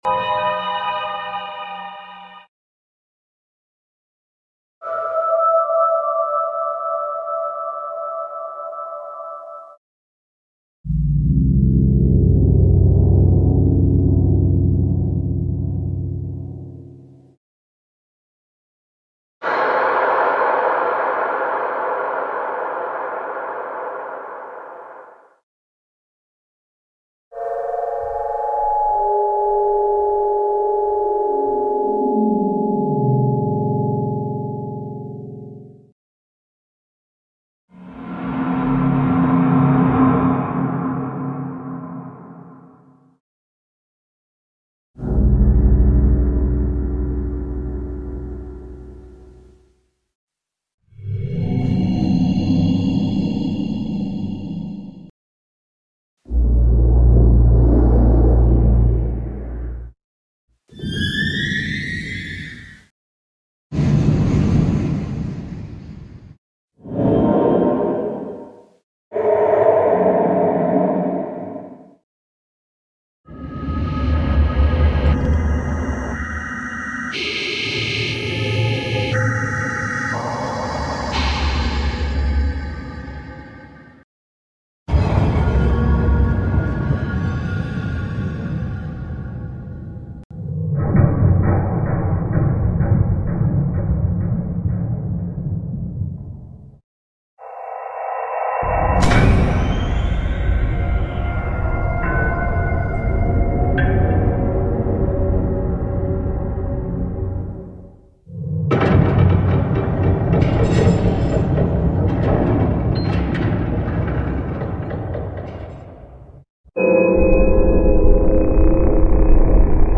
Minecraft cave sounds but they sound effects free download
Minecraft cave sounds but they are unsettling monsters